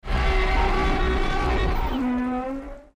Звуки Годзиллы
Здесь вы найдете его мощный рев, грохот шагов, звуки разрушений и другие эффекты из фильмов и комиксов.
Звук где Годзилла стонет от боли